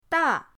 da4.mp3